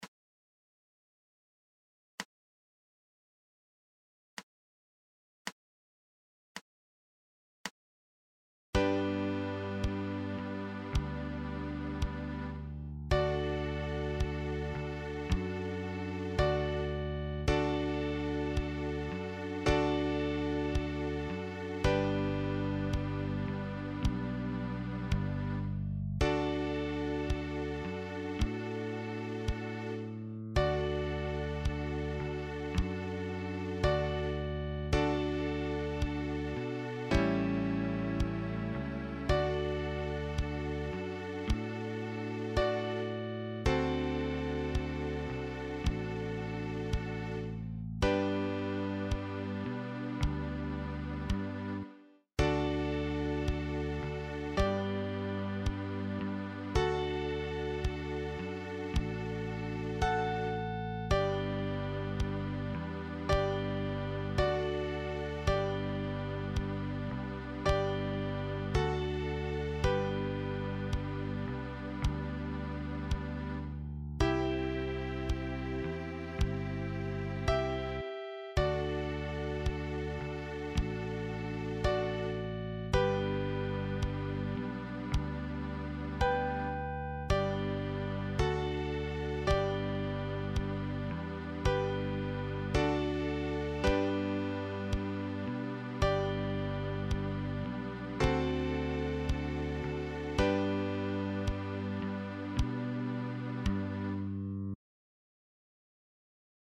Chromatische Mundharmonika